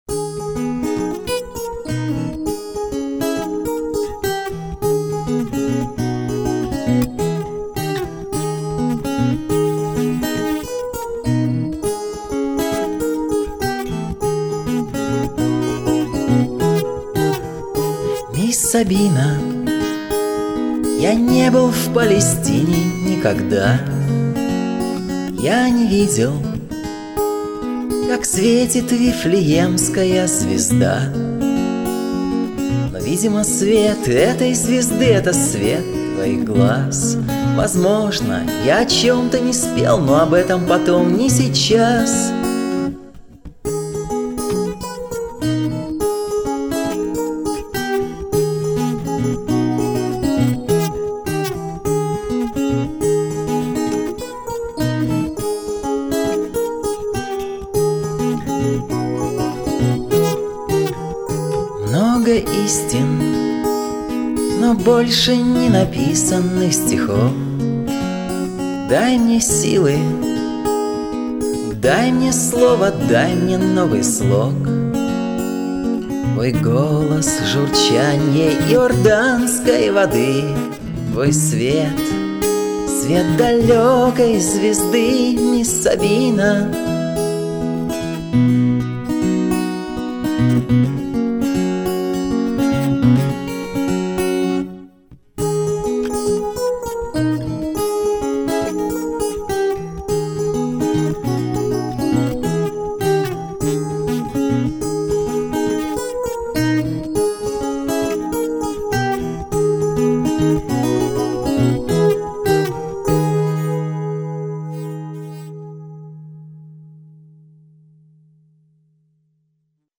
Есть в альбоме и Рок`н`Ролл
вокал, бэк вокал, акустические гитары